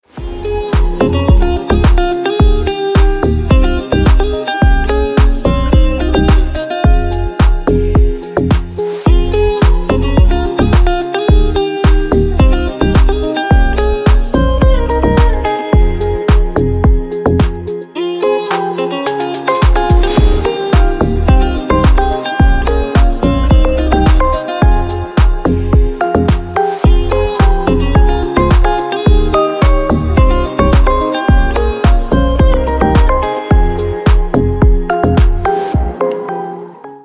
• Песня: Рингтон, нарезка
играет Спокойные звонки, спокойные рингтоны🎙